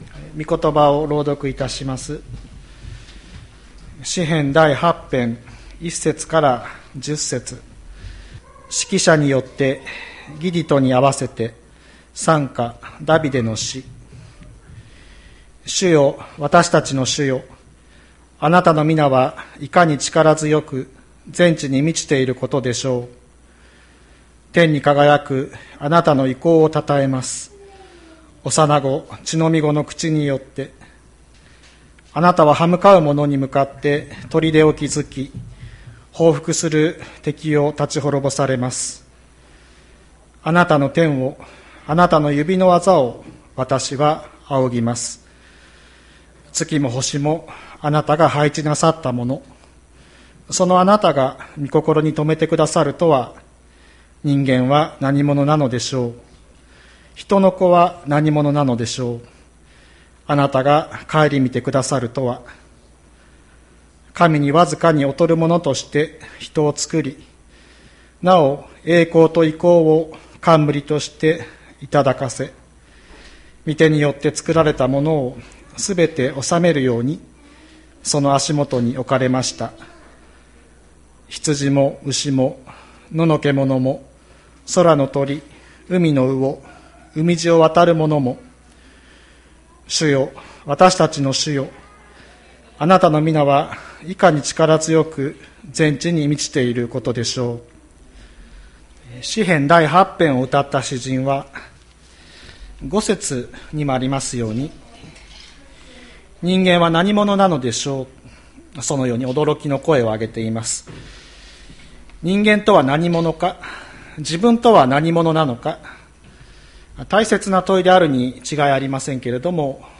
2022年02月13日朝の礼拝「人間とは何ものか」吹田市千里山のキリスト教会
千里山教会 2022年02月13日の礼拝メッセージ。